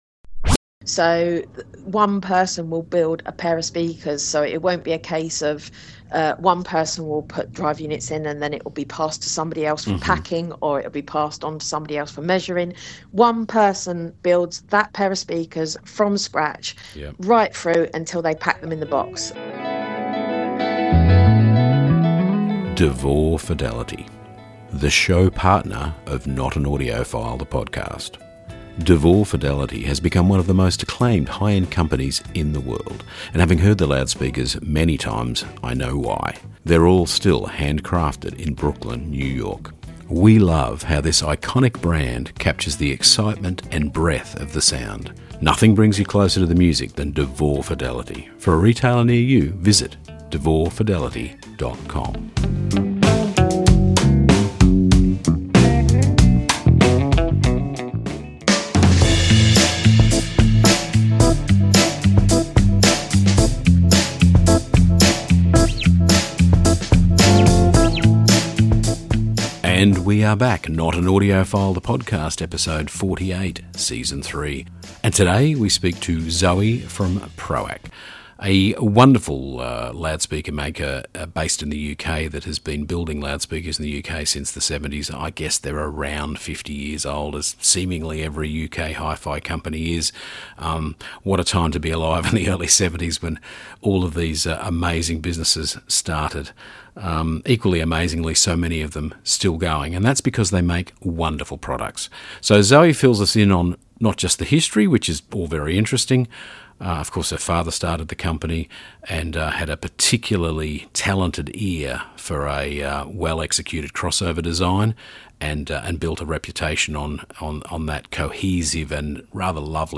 A rare and exclusive interview with the third generation of ProAc